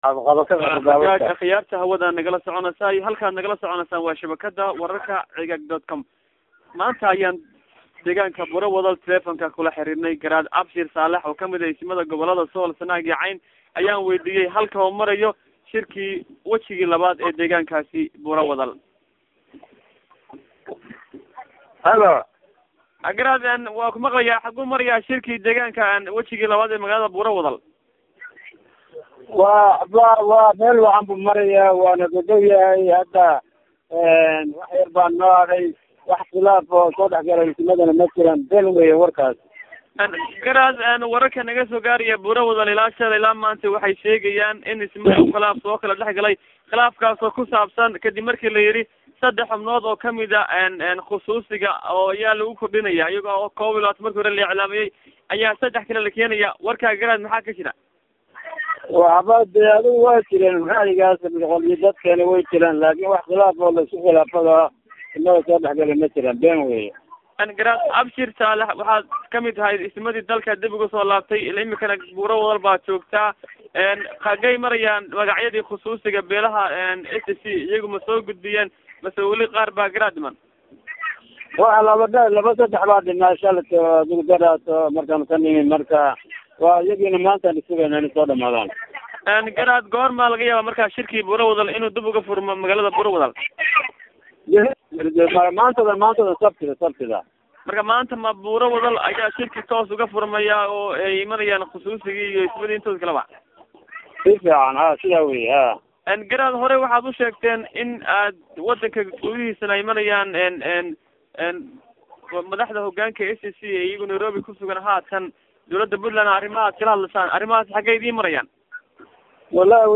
Garaadka oo Waraysi khaas ah Siinayey shabada-kada Ceegaag Online isagoo Buura-wadal ku sugan.